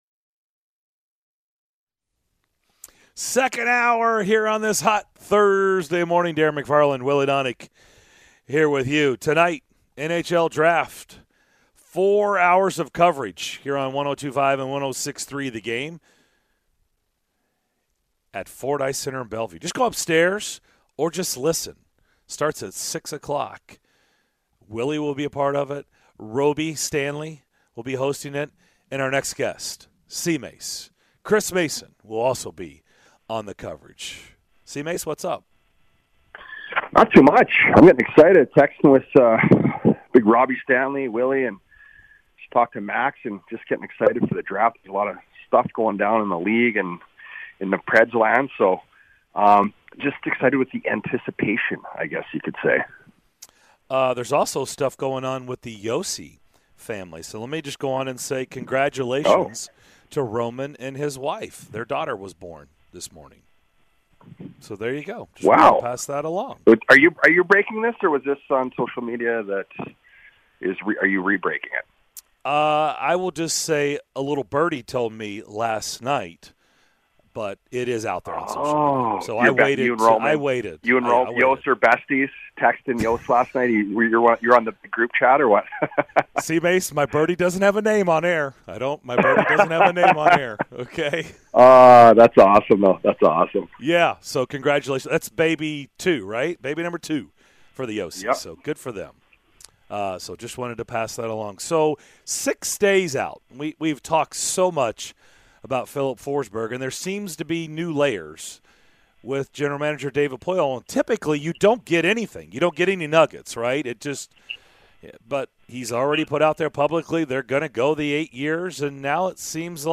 The guys talk to Preds analyst, Chris Mason, on Preds Draft day and the offseason and assess the NHL offseason thus far